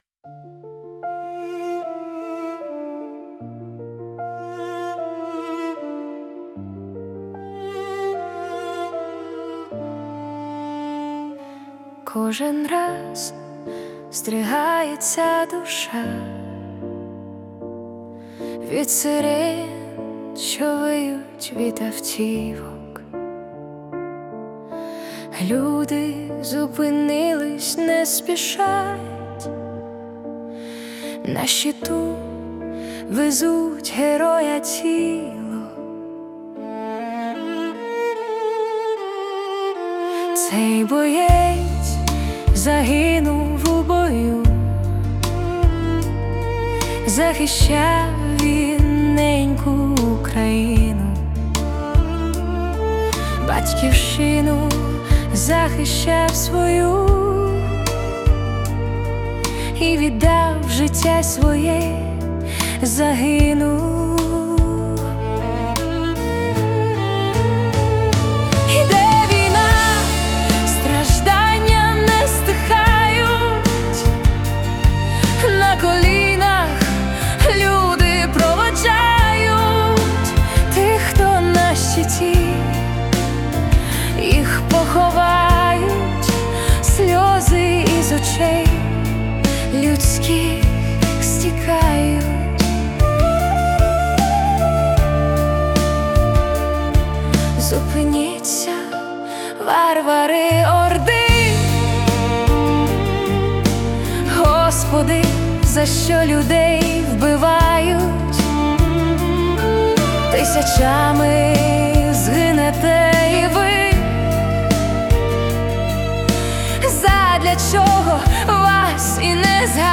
🎵 Жанр: Monologue / Requiem
Музика (77 BPM) передає біль втрати і велич подвигу.